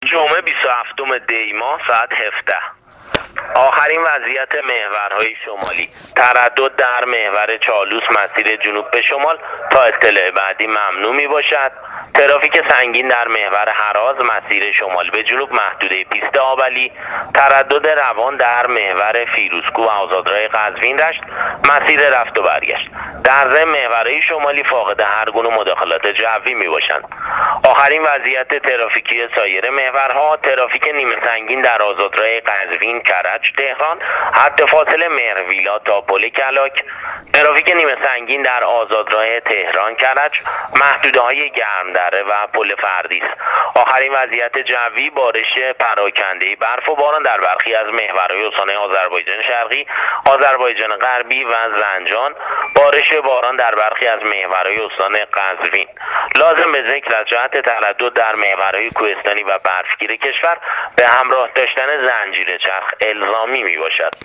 گزارش رادیو اینترنتی از آخرین وضعیت ترافیکی جاده‌ها تا ساعت ۱۷ جمعه ۲۷ دی‌ماه ۱۳۹۸: